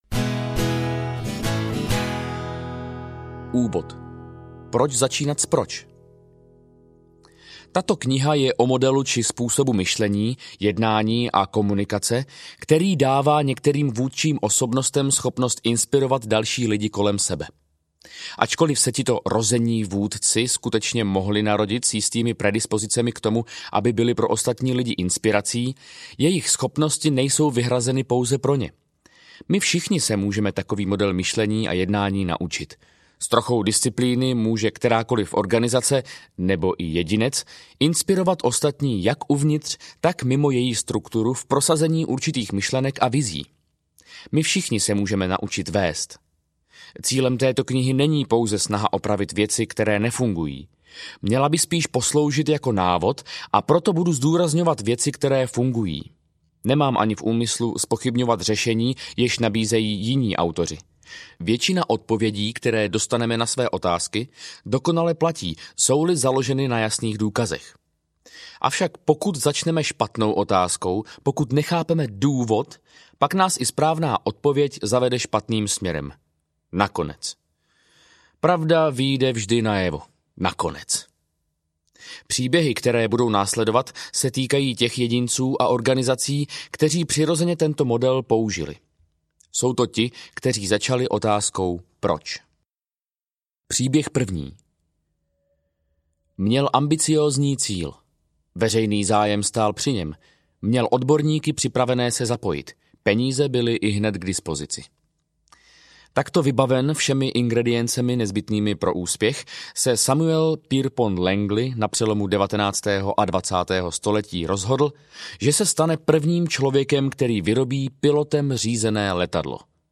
Začněte s proč audiokniha
Ukázka z knihy